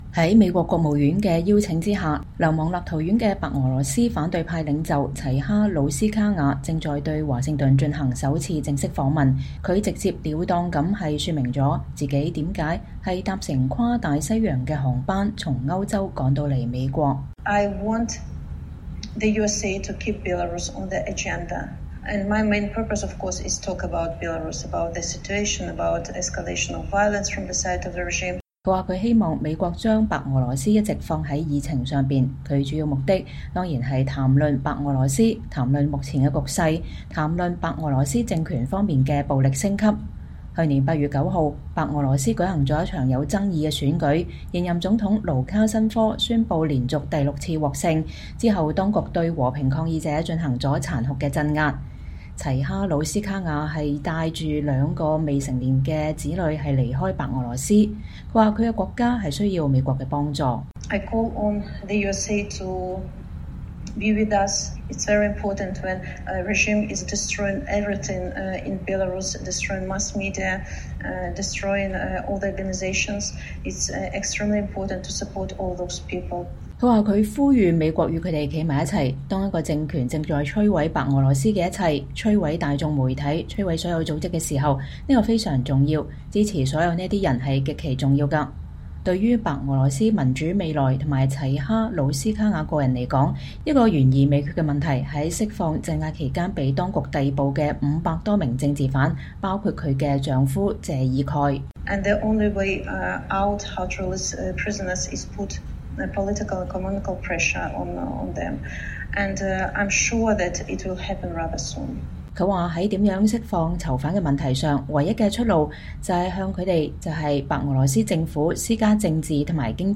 齊哈努斯卡婭在華盛頓了接受了美國之音的專訪。